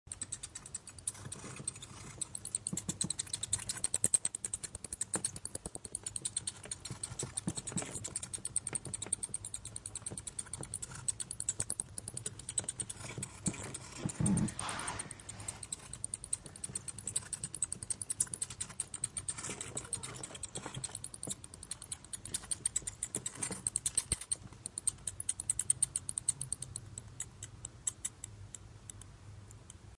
Fledermaus Geräusche
Fledermaus-Geraeusche-Wildtiere-in-Deutschland.mp3